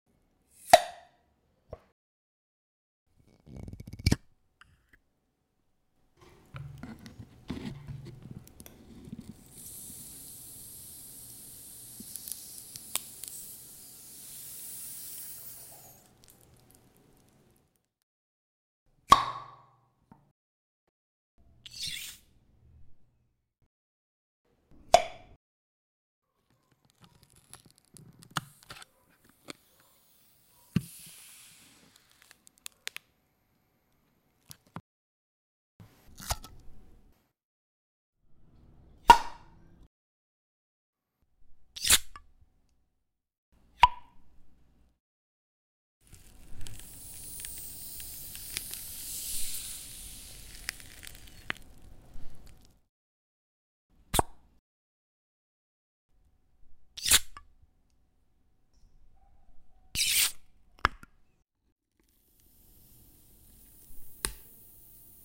Instalação: proposição sonora com escuta em fone de ouvido (ruídos de aberturas de embalagens fechadas a vácuo) e coleção de lacres brancos armazenada em dispositivos brancos
• Para escutar um fragmento de alguns fins de vácuos: